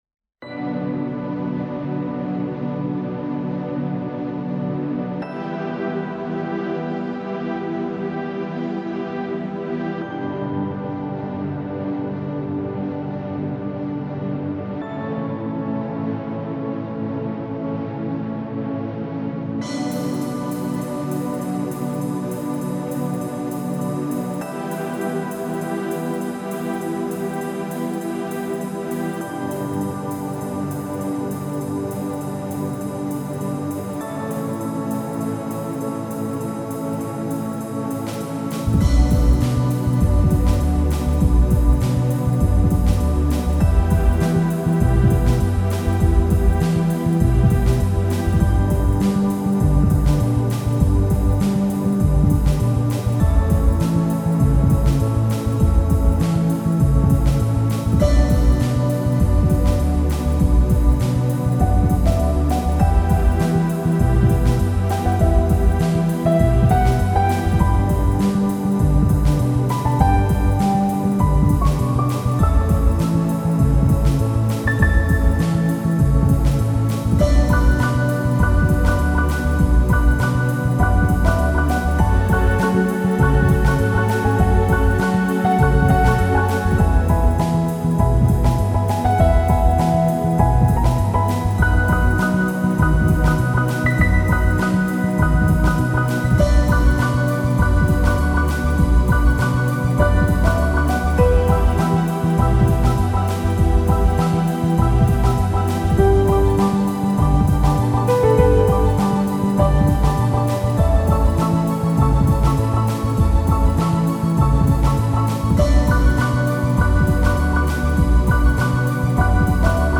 4:30 – Ballad / Symphonic.